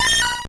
Cri de Miaouss dans Pokémon Or et Argent.